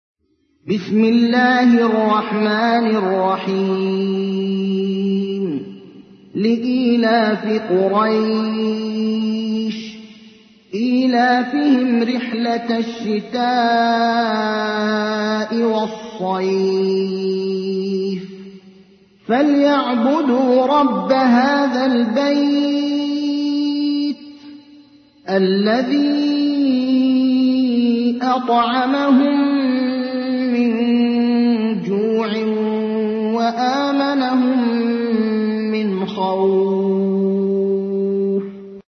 تحميل : 106. سورة قريش / القارئ ابراهيم الأخضر / القرآن الكريم / موقع يا حسين